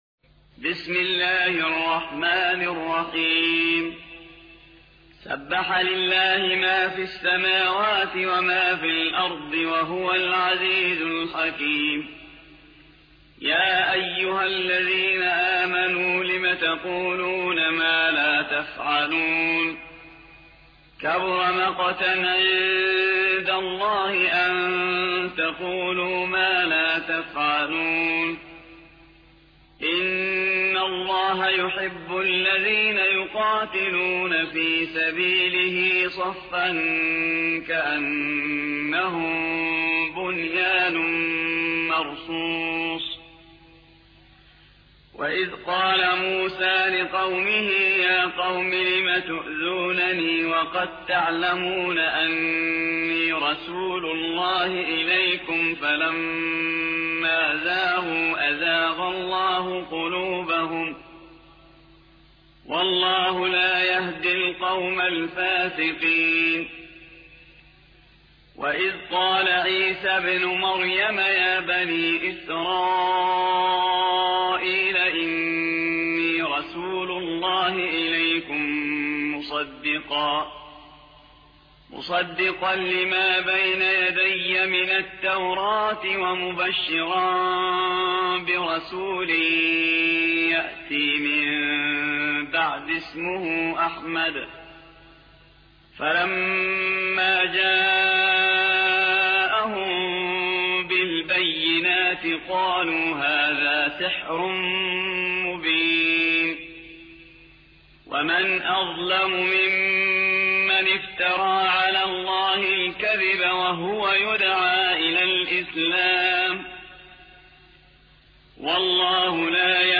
61. سورة الصف / القارئ